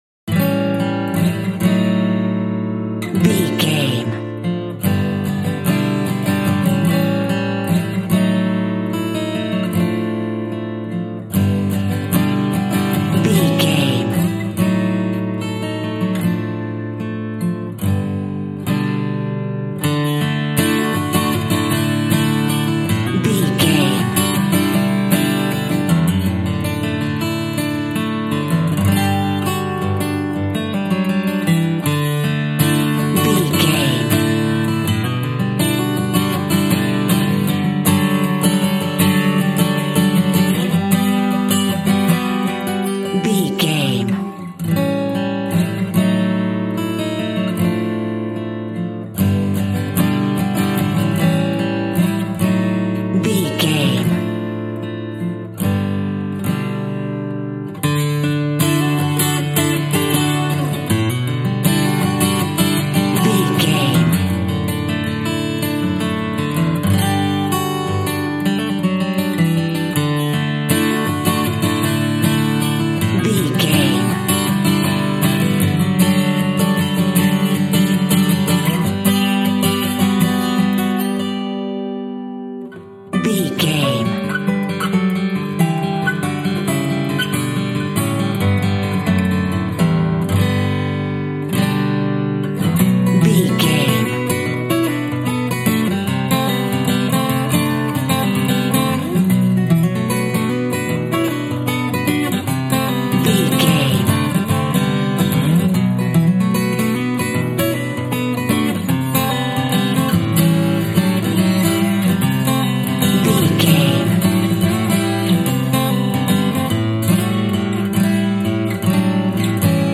Good times, relaxing, friends, party, peaceful, content
Lydian
acoustic guitar
percussion